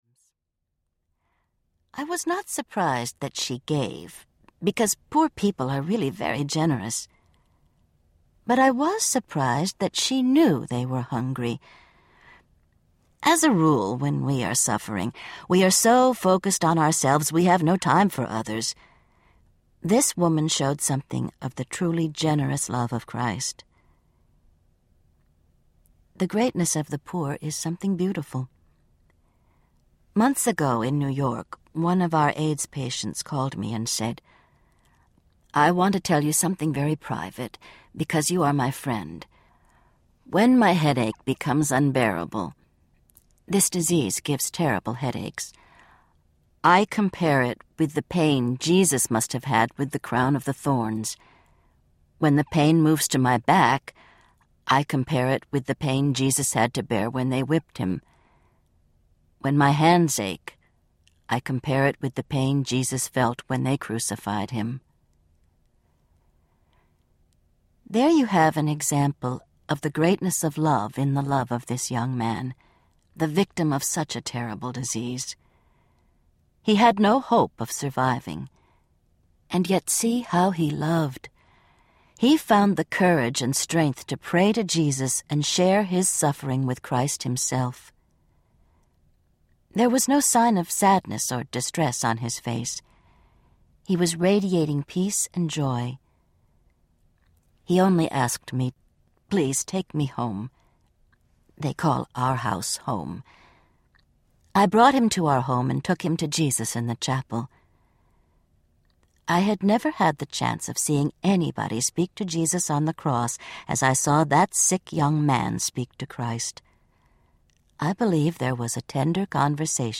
Loving Jesus Audiobook
Narrator
3.25 Hrs. – Unabridged